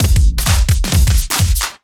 OTG_DuoSwingMixC_130a.wav